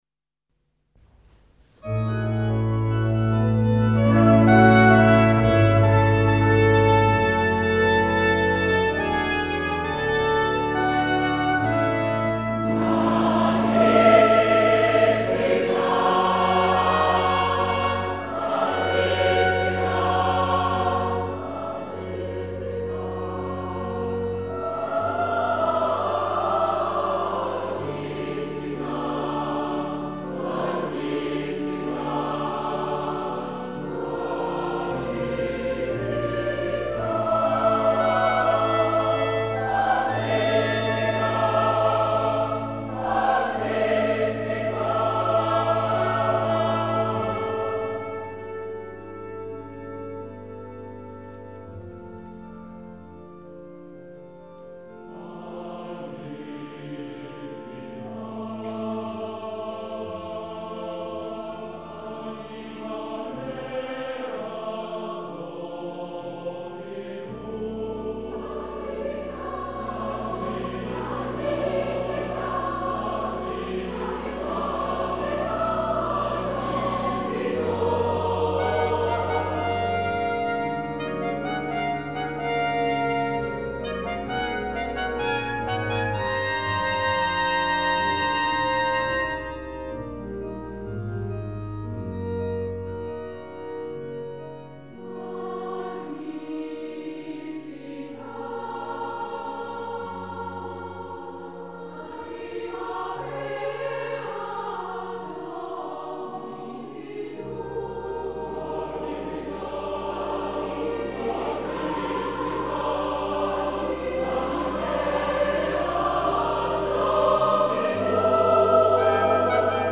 SATB + soli + 2 trompettes et orgue ou ensemble instrumental
1ère version : chœur, 2 trompettes en ut et grand orgue.